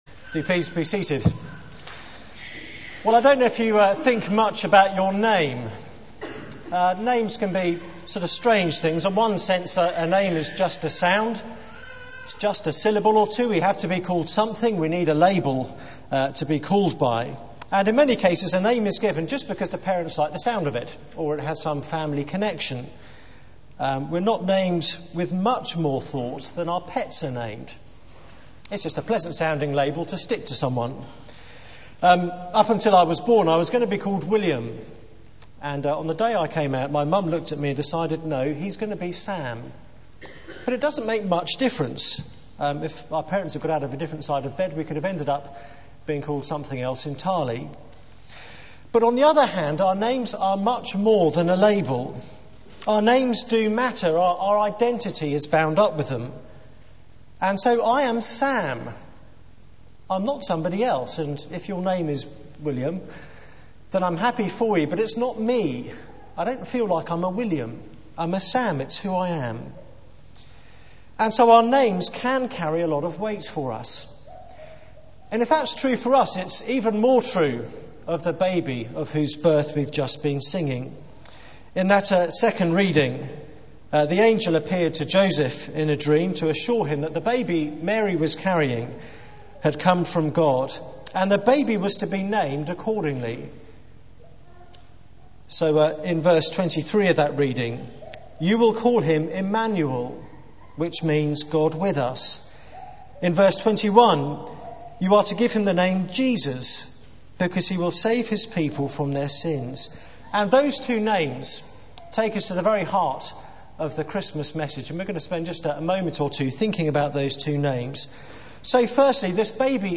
Media for 9:15am Service on Mon 15th Dec 2008 13:10 Speaker: Passage: Series: Theme: Town Carol Service Sermon Search the media library There are recordings here going back several years.